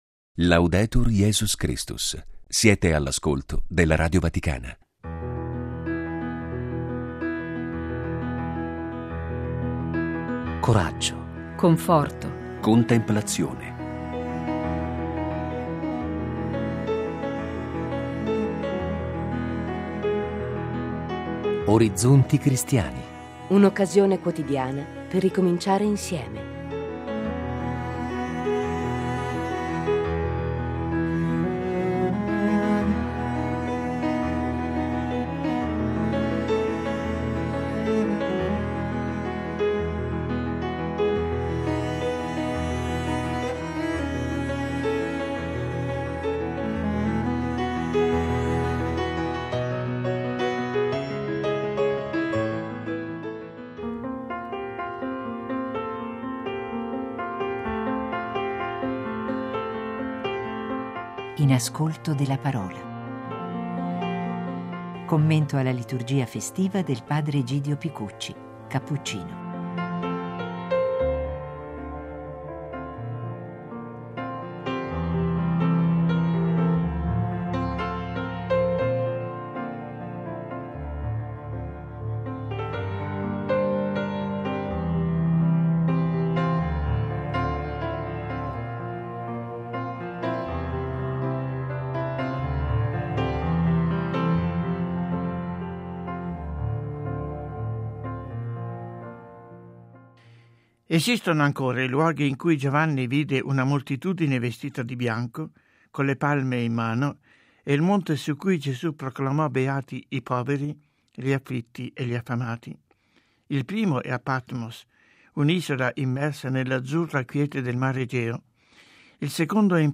il commento alla liturgia festiva scritto e proposto al microfono